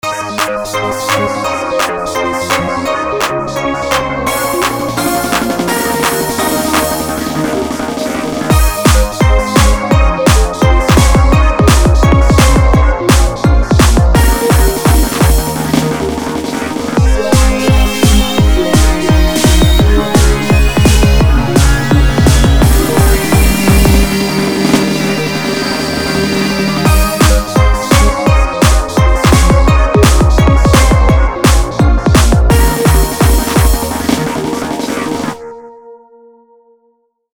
An instrumental recording.